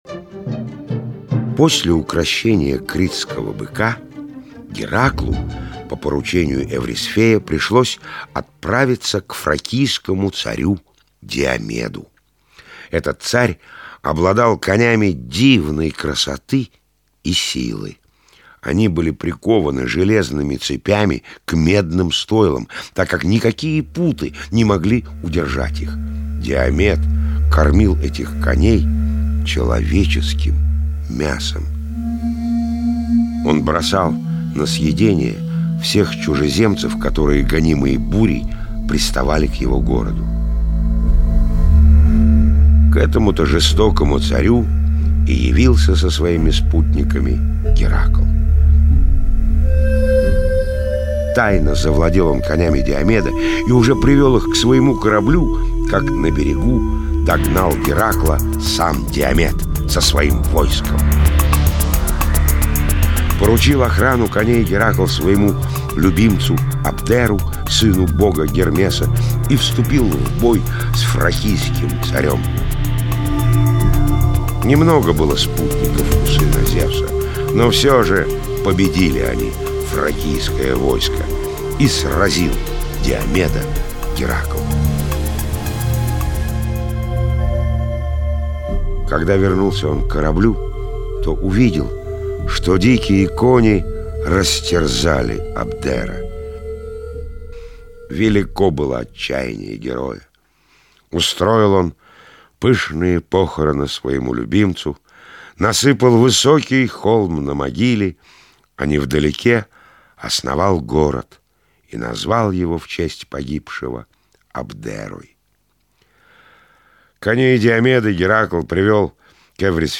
Аудиосказка «Подвиги Геракла»
Прекрасно подойдешь для слушателей 6-8 лет, но не очень удобно слушать детям по старше так как слишком много музыки и пауз.